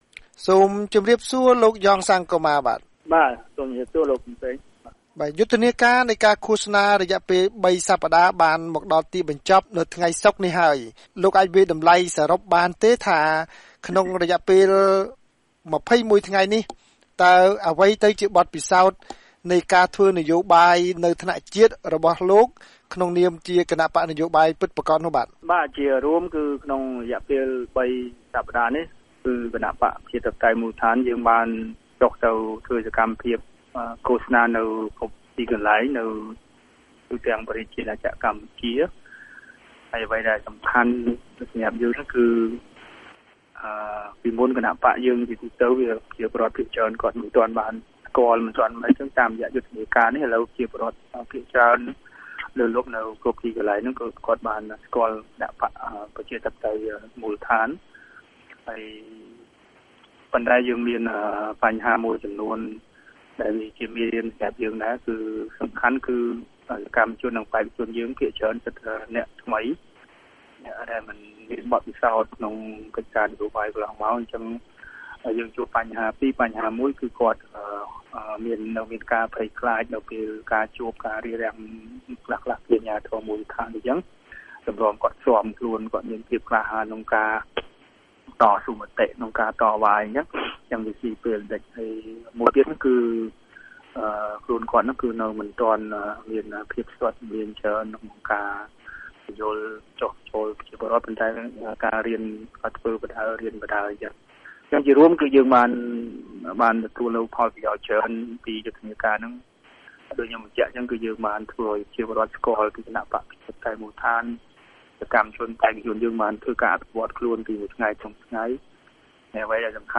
បទសម្ភាសន៍ VOA ៖ បក្សមូលដ្ឋានមានសុទ្ទិដ្ឋិនិយមនឹងការឃោសនា ទោះជាមានឧបសគ្គ